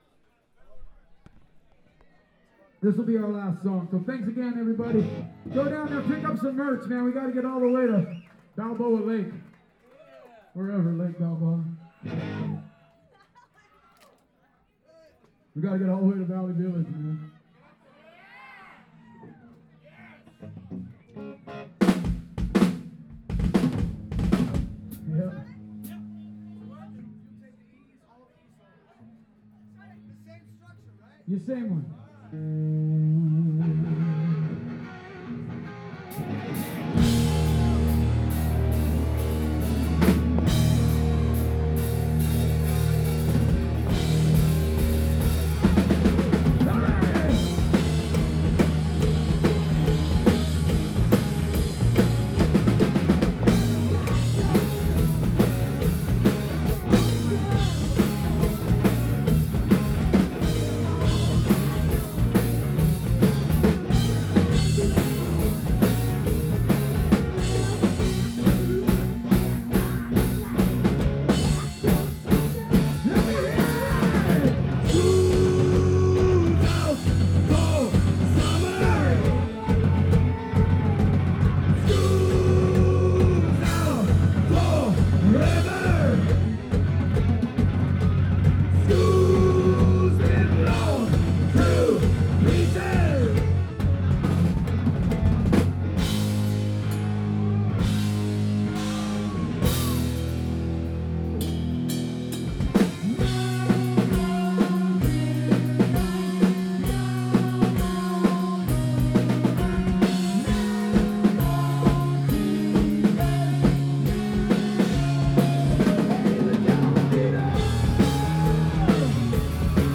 Heavy rock/ metal band
the tour concluded with a fabulous show at The Viper Room
The Viper Room, West Hollywood, CA, USA 2015